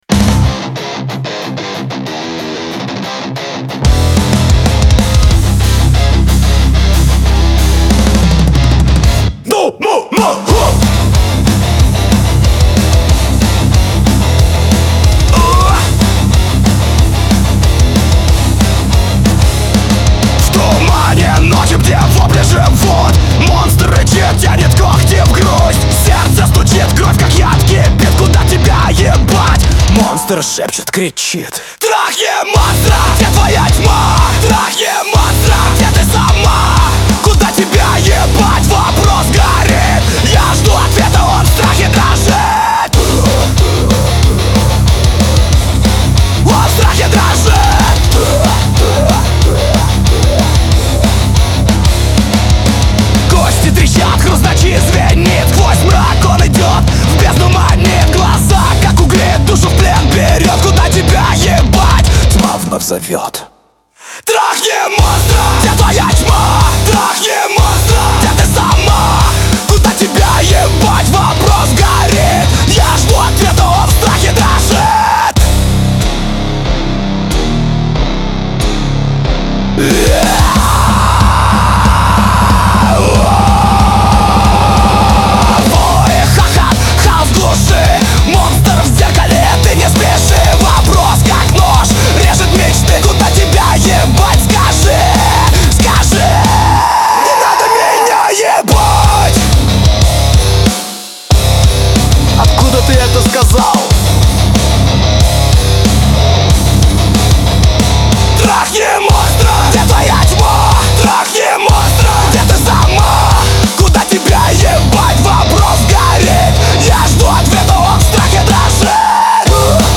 Категории: Русские песни, Метал.